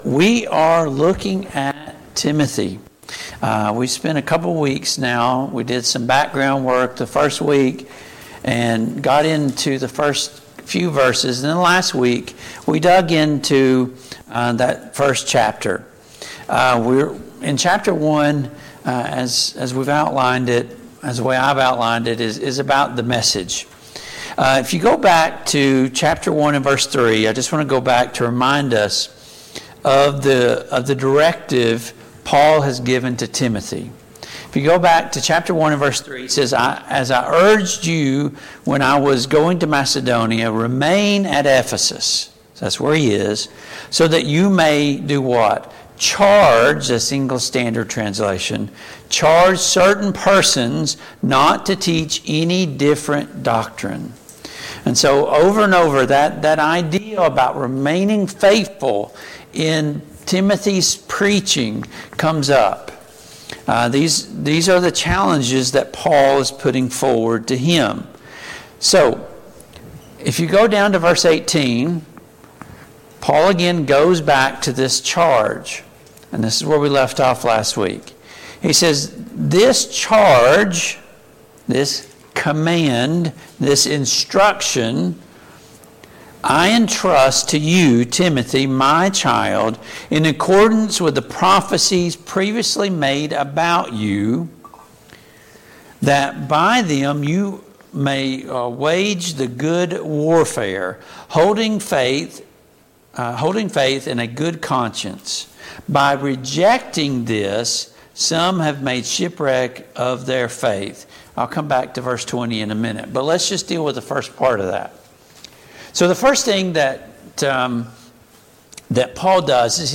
Passage: 1 Timothy 1:18-20; 1 Timothy 2:1-4 Service Type: Mid-Week Bible Study